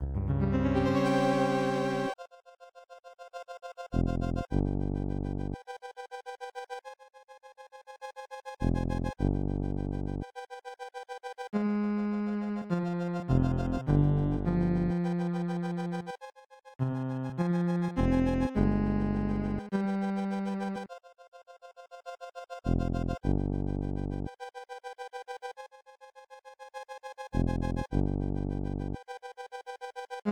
Description Castle BGM